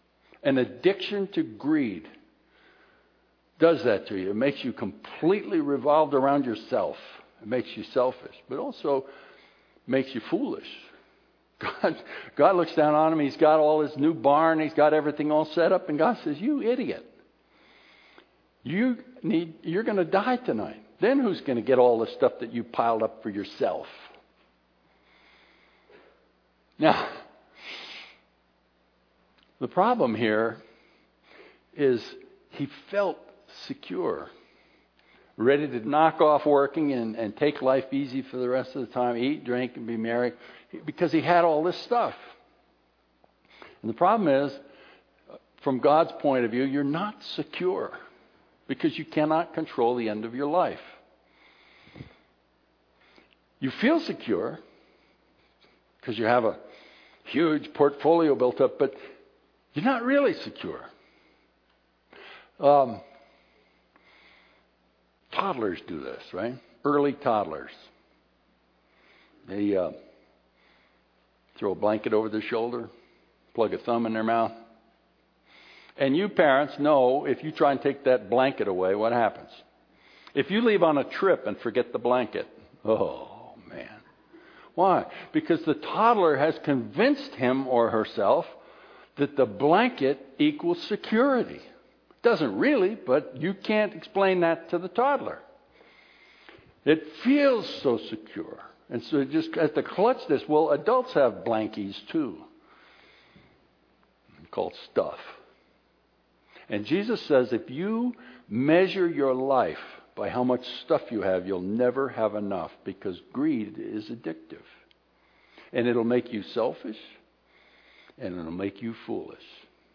What do many rich guys have in common with toddlers?  You can find out in this continuation of the Thanksgiving message posted last time (See Happy + Thanks + Giving).